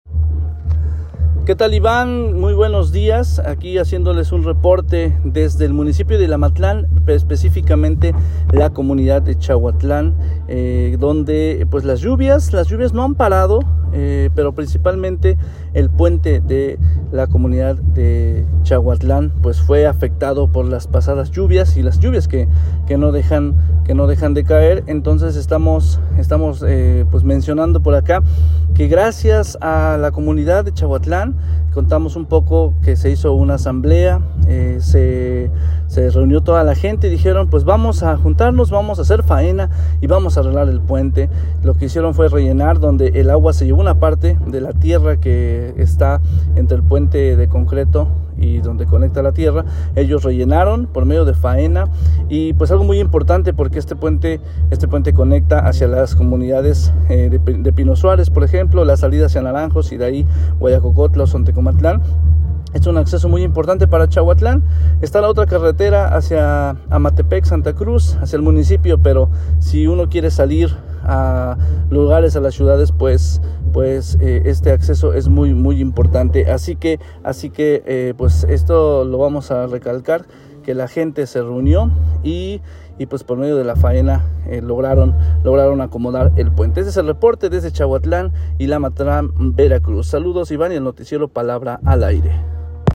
Desde esta comunidad nos comparte el reporte